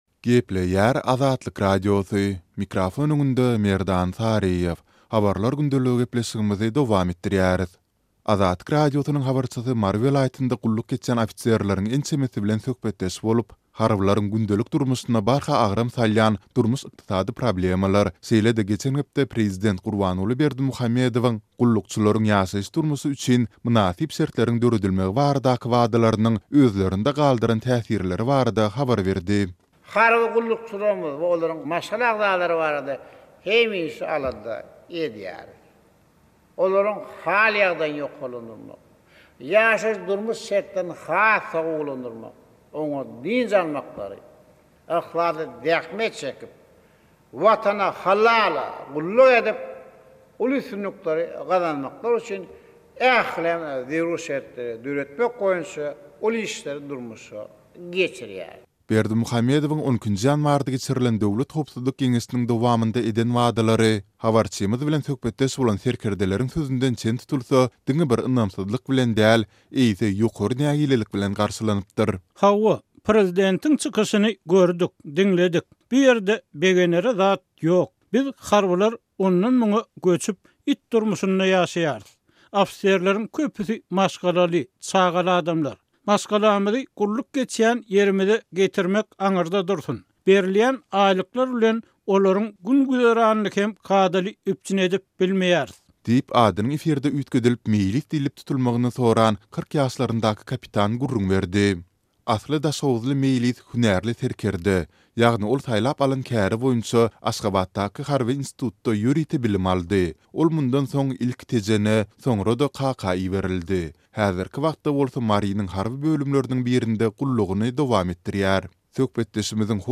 Azatlyk Radiosynyň habarçysy Mary welaýatynda gulluk geçýän ofiserleriň ençemesi bilen söhbetdeş bolup, harbylaryň gündelik durmuşyna barha agram salýan durmuş-ykdysady problemalar, şeýle-de geçen hepde prezident Gurbanguly Berdimuhamedowyň gullukçylaryň ýaşaýyş-durmuşy üçin “mynasyp şertleriň” döredilmegi baradaky wadalarynyň özlerinde galdyran täsirleri barada habar berdi.